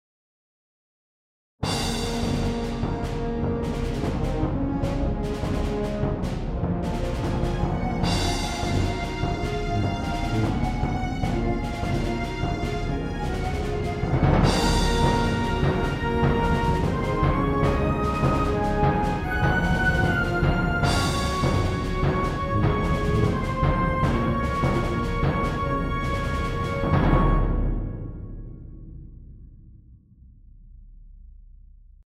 【BPM150】